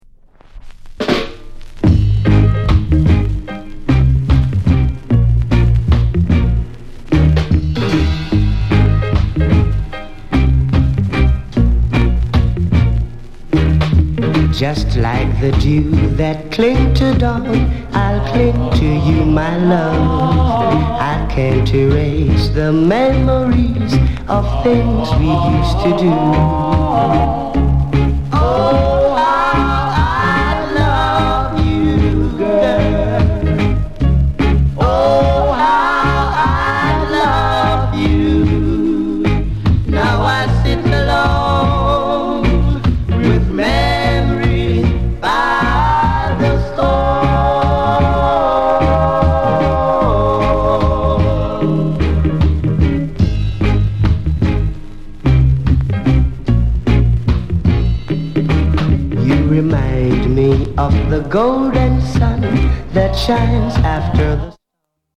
ROCKSTEADY